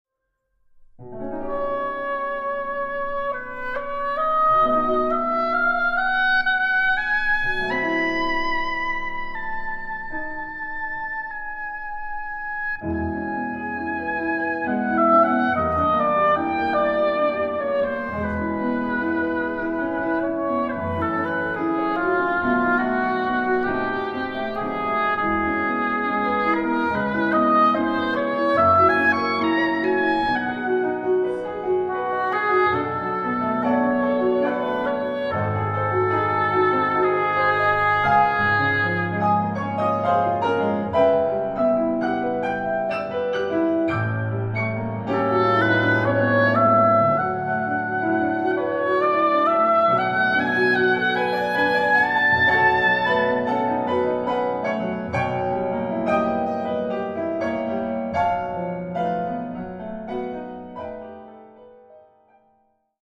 all recorded in a warm studio acoustic.